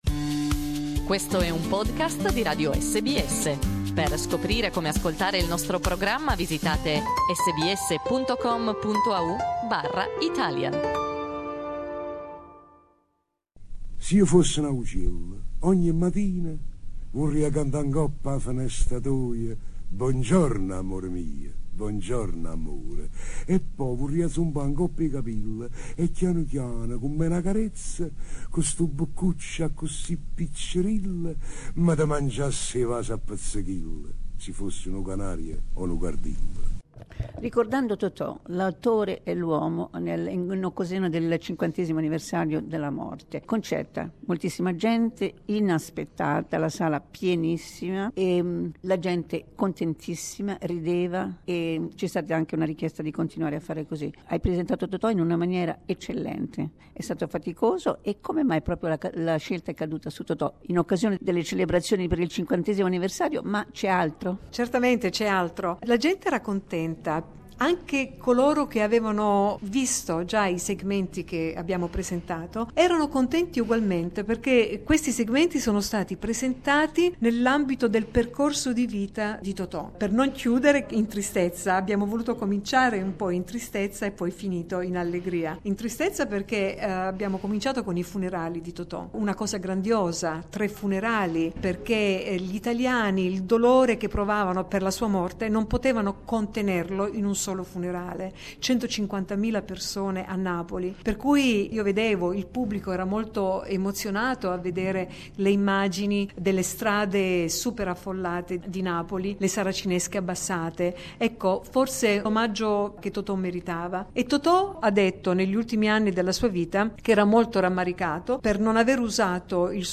interviewed after the conference